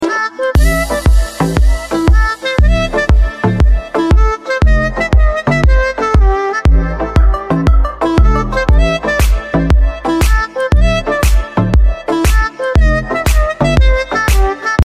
• Качество: 128, Stereo
громкие
мелодичные
без слов
инструментальные
Саксофон
house
Приятный рингтон со звонким саксофоном.